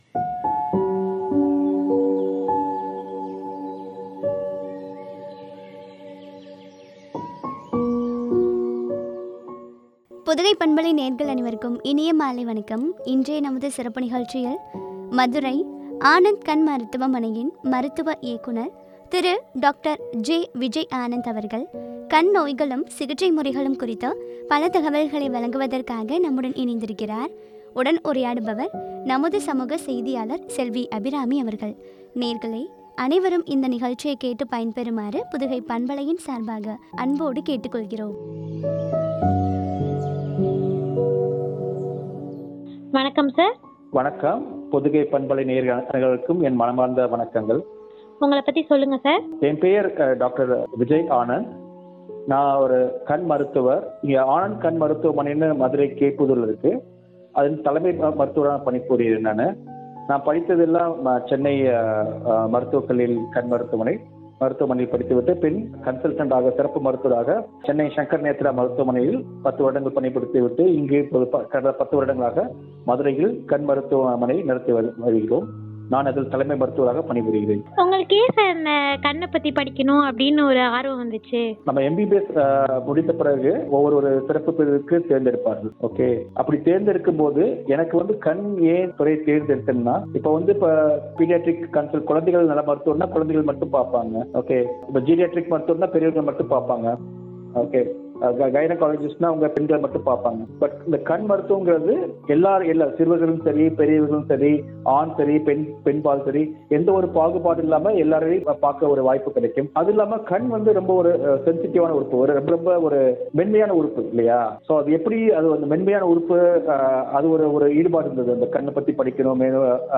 சிகிச்சை முறைகளும் பற்றிய உரையாடல்.